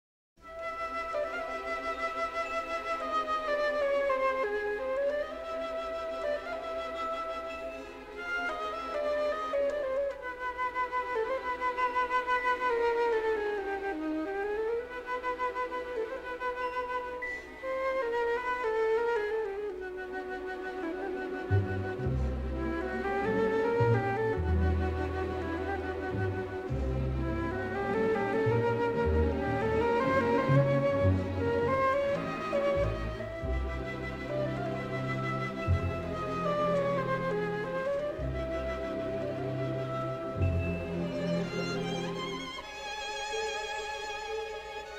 with harmonic-minor twists both sinister and mysterious.